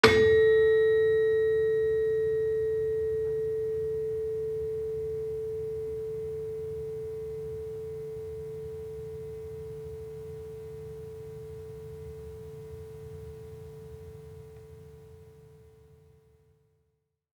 HSS-Gamelan-1
Gender-4-A3-f.wav